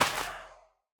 Minecraft Version Minecraft Version 1.21.5 Latest Release | Latest Snapshot 1.21.5 / assets / minecraft / sounds / block / soul_sand / break1.ogg Compare With Compare With Latest Release | Latest Snapshot